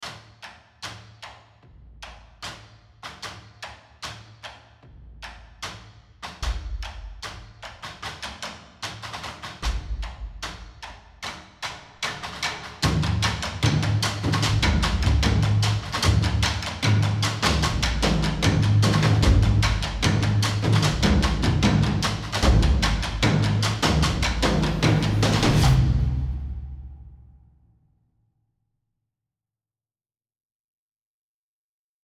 drum_beat.wav